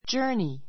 journey A2 dʒə́ː r ni ヂャ ～ニ 名詞 旅行 travel 類似語 go on a journey go on a journey 旅行に行く set out on [start on] a journey set out on [start on] a journey 旅行に出発する They are now on a car journey from Paris to Rome.